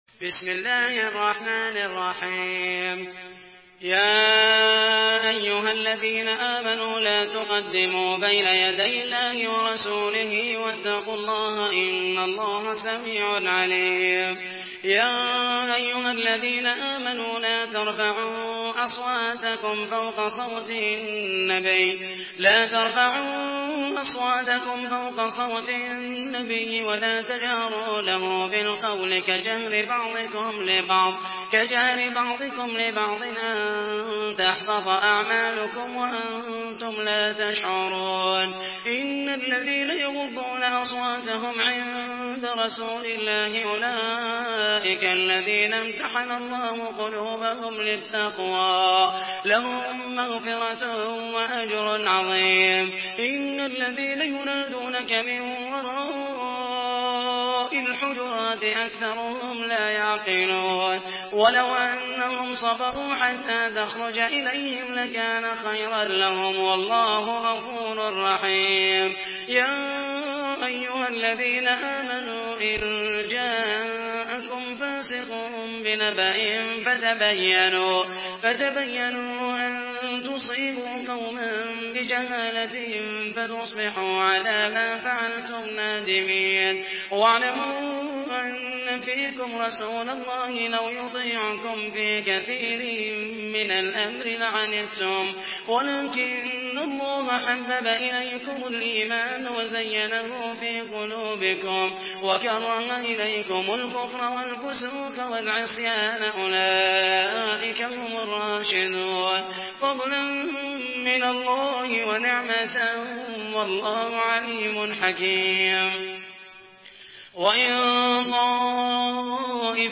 Surah Al-Hujurat MP3 by Muhammed al Mohaisany in Hafs An Asim narration.
Murattal Hafs An Asim